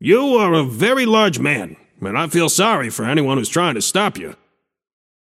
shopkeeper voice line - You are a very large man. But don’t feel sorry for anyone who’s trying to stop you.